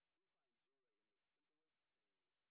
sp07_white_snr10.wav